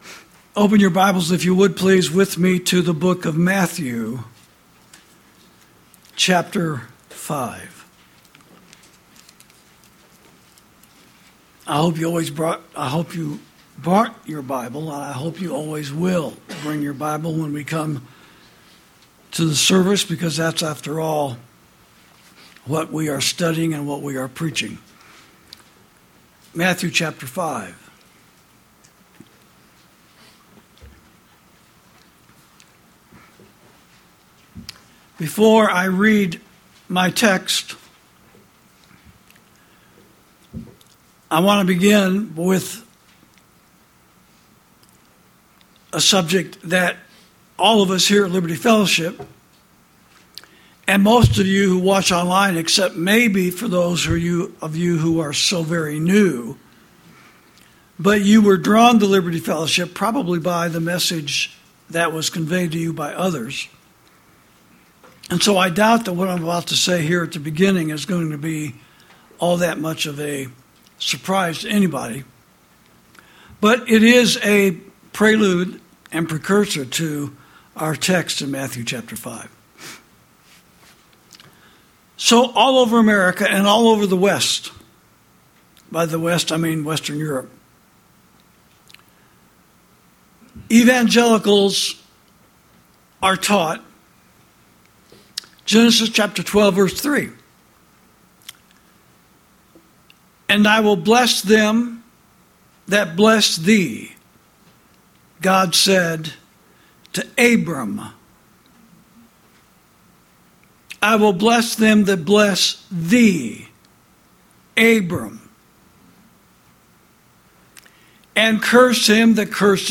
Sermons > Who Did Jesus Say Would Be "Blessed"?